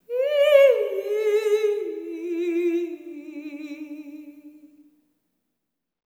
ETHEREAL02-L.wav